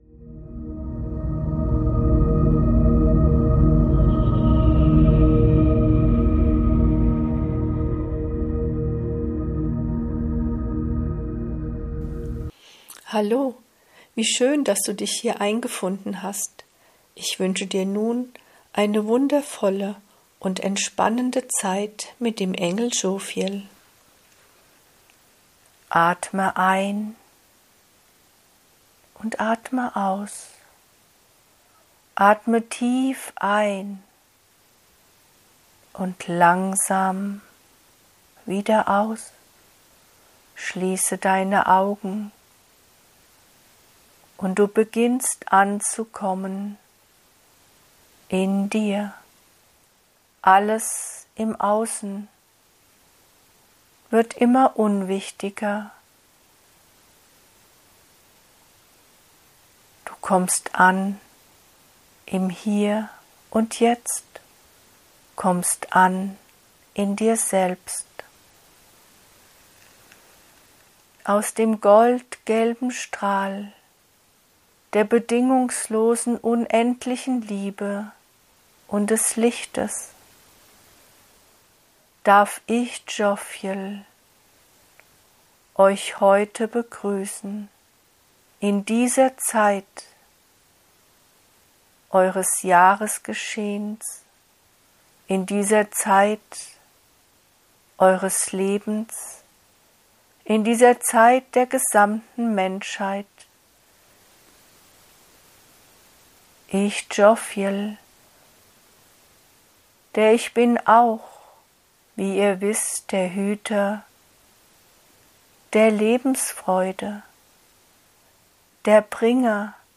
In meinem Podcast findest du durch mich direkt gechannelte Lichtbotschaften. Wundervoll geeignet zum meditieren, vom Alltag abschalten und abtauchen in eine andere Ebene des Seins.